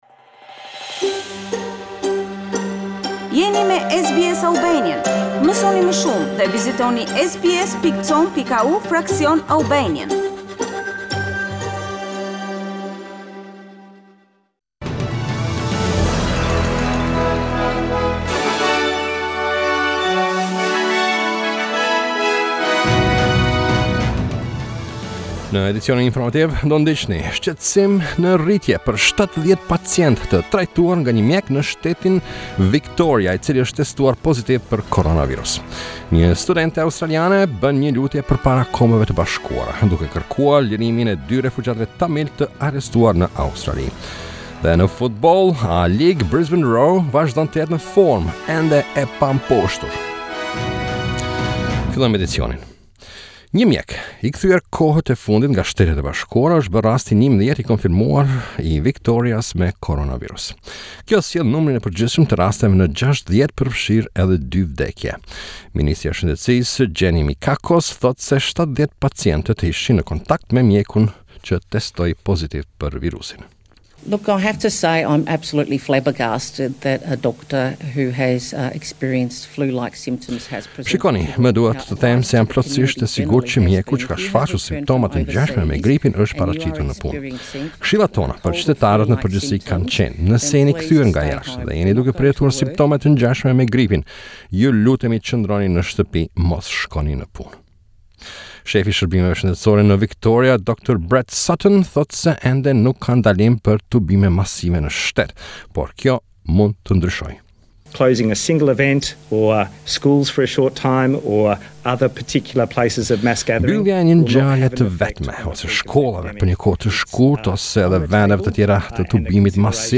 SBS News Bulletin 07 March 2020